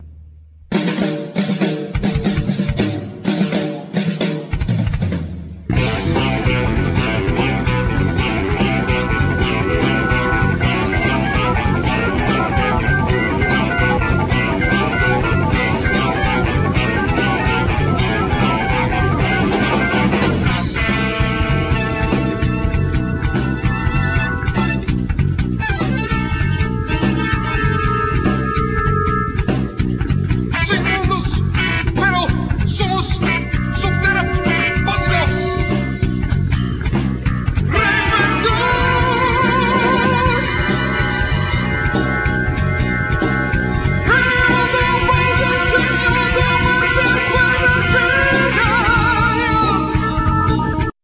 Guitar
Violin
Keyboards
Sax
Trumpet
Trombone